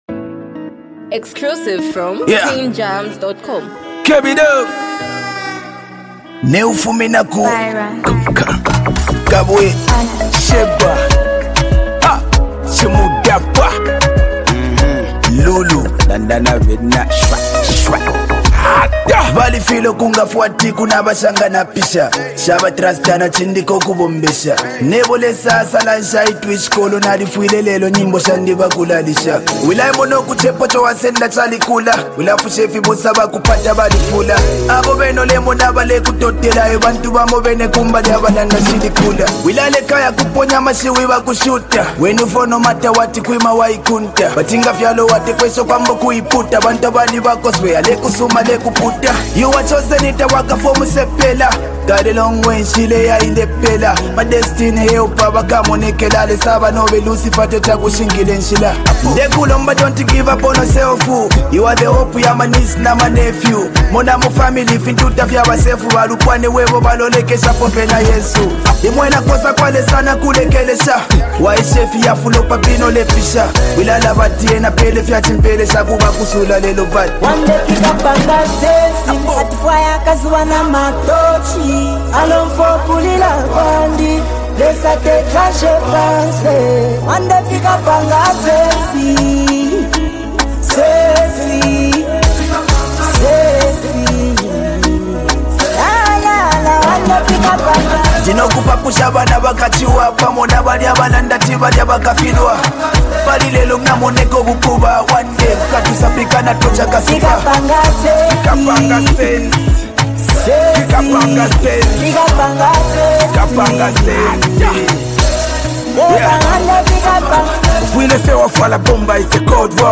a motivational and reflective song
signature rap style, bringing energy and raw honesty